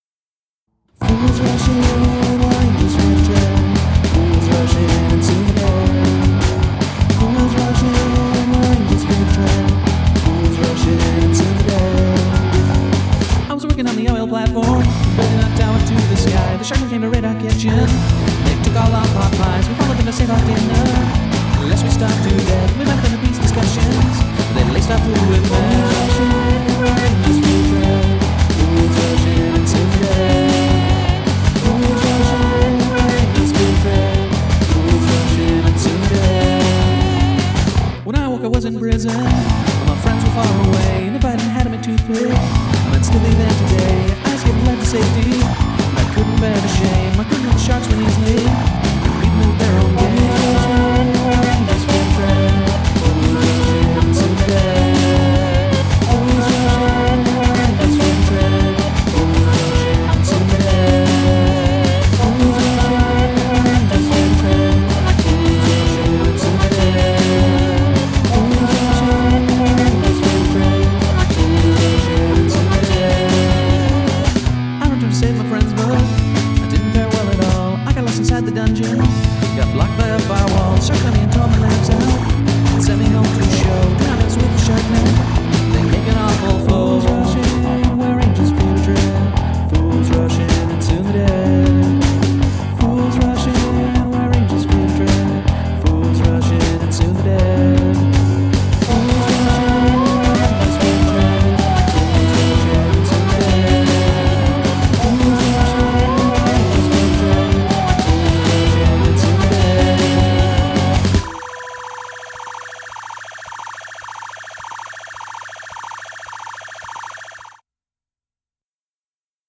There will also be much shenanigans with procedurally-generated beats and synths...
Some of the issues I'm noticing are that the lead vocals are pushed way back, the guitars are mostly just in the side channels (which is leading to some mono compatibility issues). There's also a scratchy sounding thing in the chorus that might be a bit too loud.